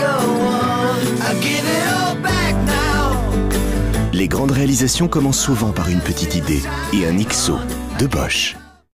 Prestation Voix-Off - Ixo de Bosch : ton naturel, simple et amical
Voix simple et amicale.
Voix-off pour la publicité de l’Ixo de Bosch.
Pour l’Ixo de Bosch, j’ai utilisé une hauteur de voix médium grave, qui correspond à l’esprit de l’outil : robuste, fiable et sérieux, tout en restant accessible et convivial.
La tonalité de ma voix pour cette publicité était naturelle, simple et directe.